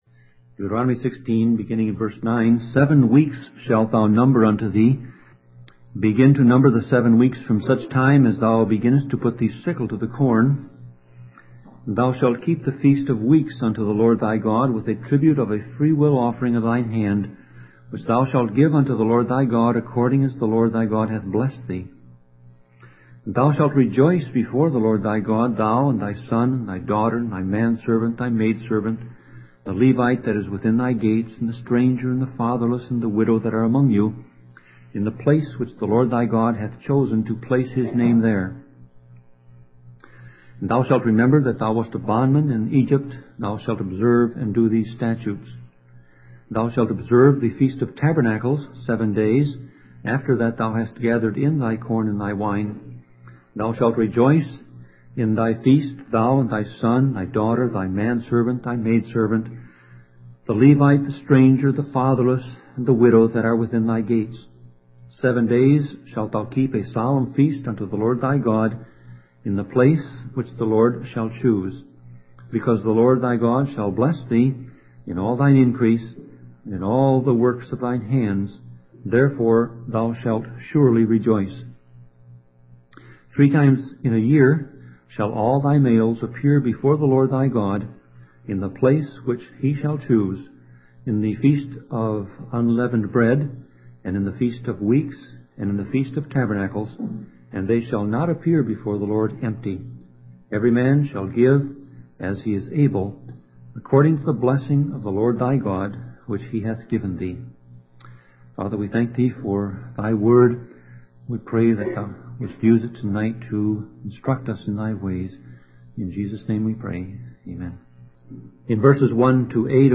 Series: Sermon Audio